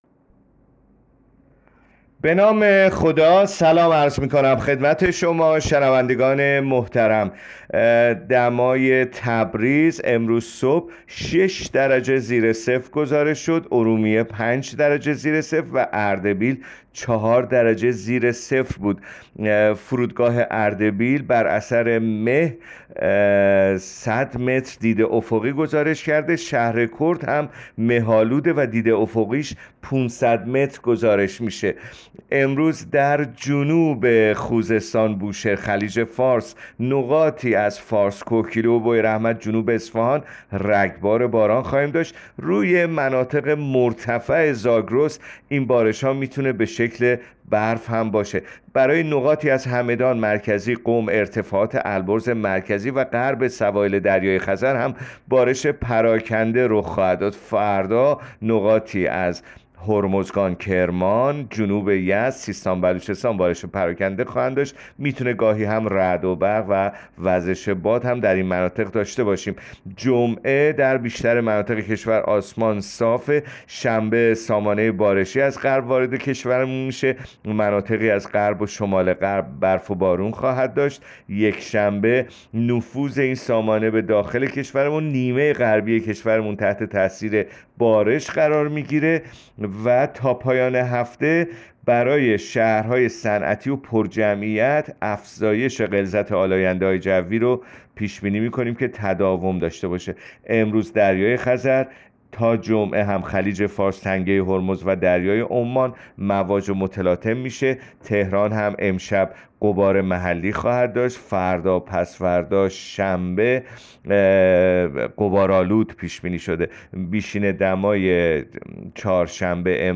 گزارش رادیو اینترنتی پایگاه‌ خبری از آخرین وضعیت آب‌وهوای ۱۹ دی؛